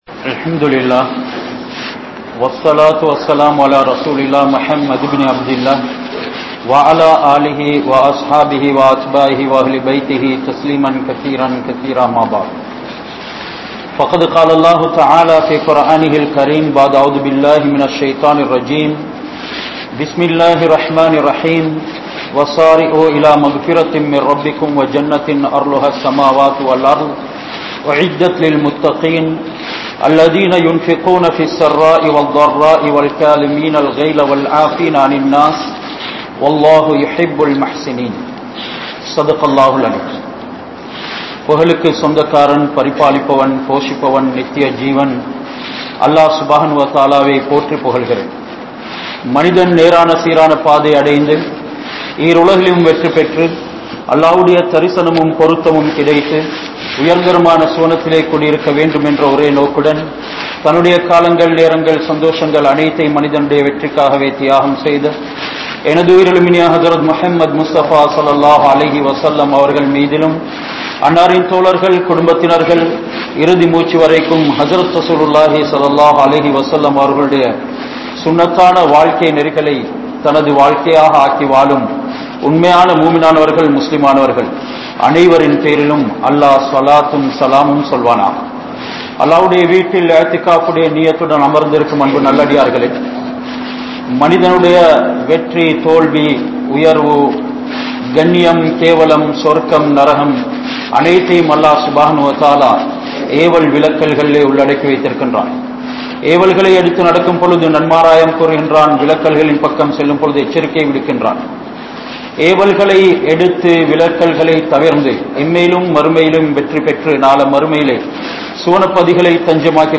Suvarkam Yaarukkuriyathu? (சுவர்க்கம் யாருக்குரியது?) | Audio Bayans | All Ceylon Muslim Youth Community | Addalaichenai
Town Jumua Masjith